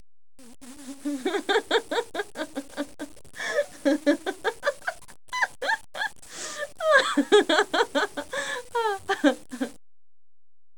Rire de femme